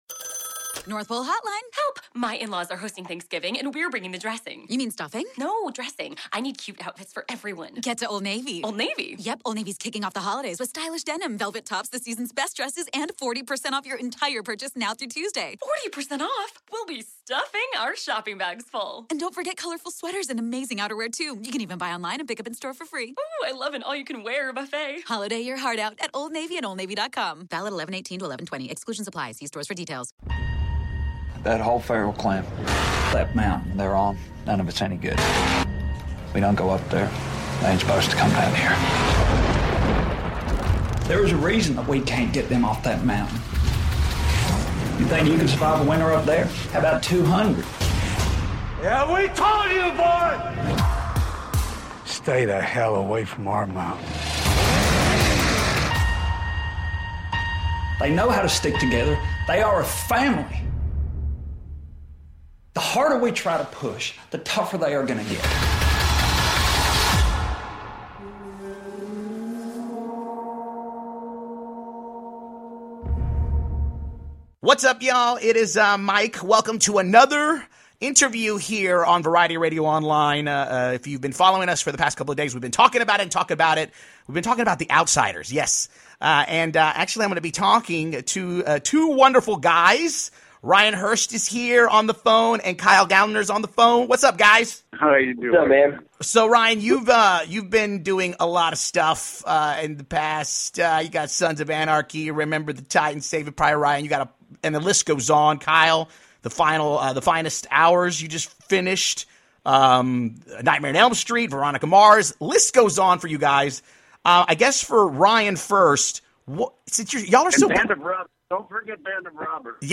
Variety Radio Online is bringing you another interview!